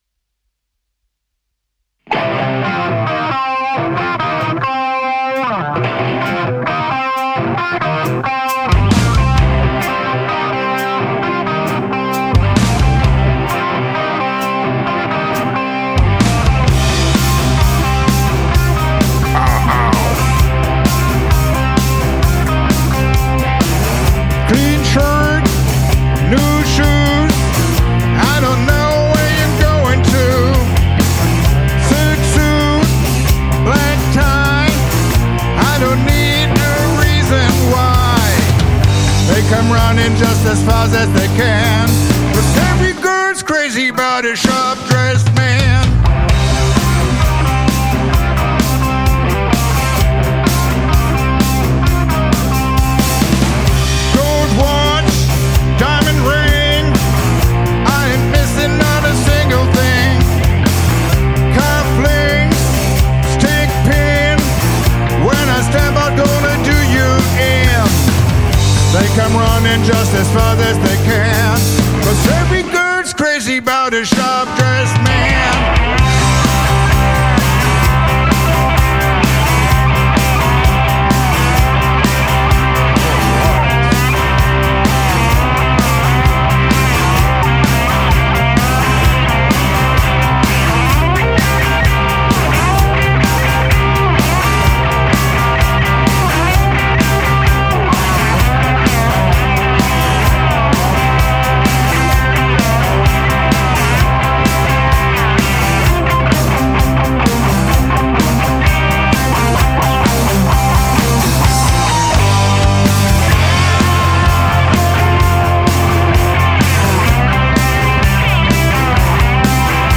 Gesang und Gitarre
Backing Vocals und Leadgitarre
Bass
Schlagzeug